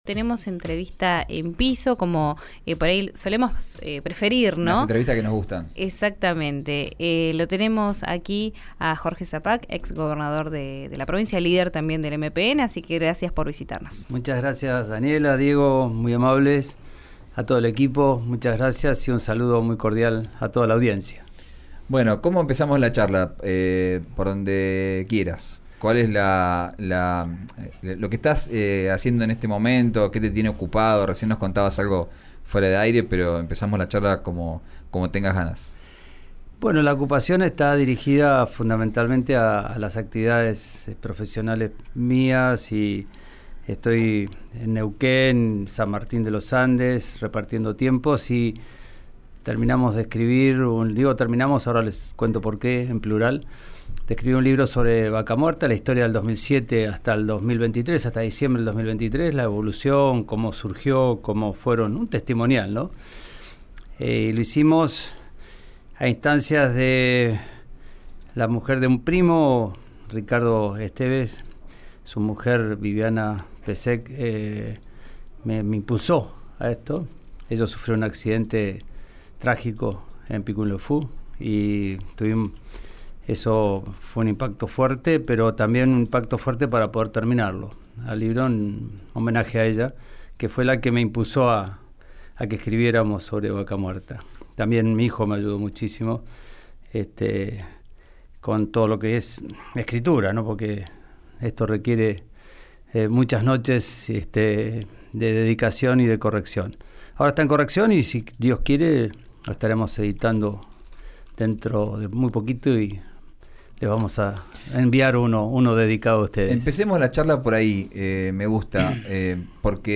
En una entrevista con RÍO NEGRO RADIO, habló también sobre la ley de presentismo docente en la provincia, la posibilidad de volver a ser candidato y sobre el cargo en la Unesco que le ofrecieron a la senadora Lucila Crexell.
Escuchá a Jorge Sapag, exgobernador de Neuquén, en RÍO NEGRO RADIO (parte 1):